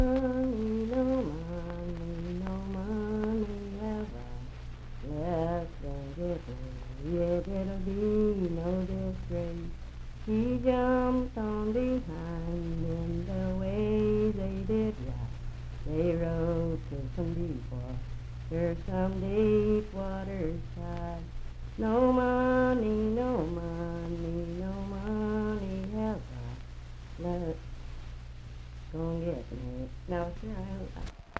Unaccompanied vocal music
Voice (sung)
Hardy County (W. Va.)